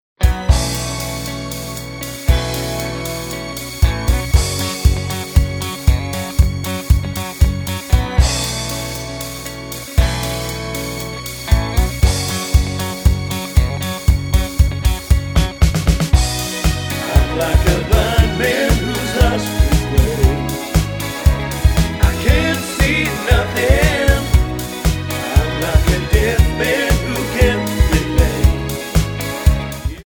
--> MP3 Demo abspielen...
Tonart:F# Multifile (kein Sofortdownload.